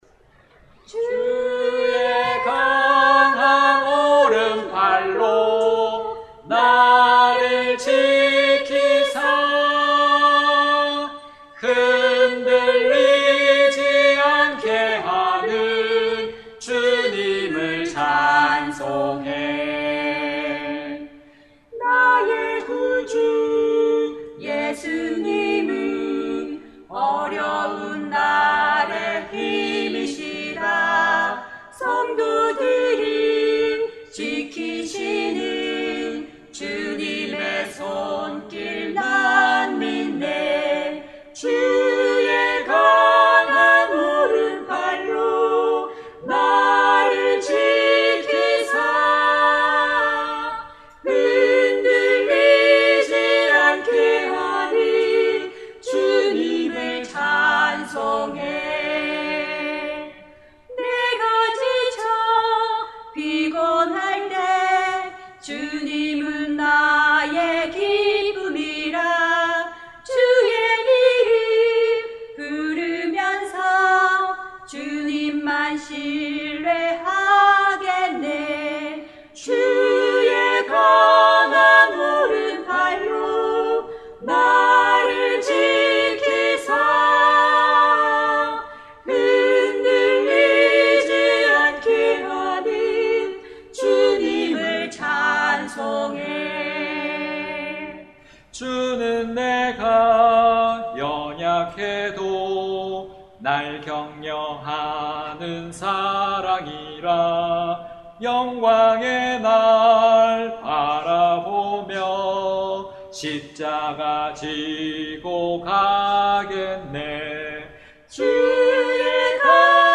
복음찬송가 듣기
0771_나의 구주 예수님은_(강릉믿음 특송).mp3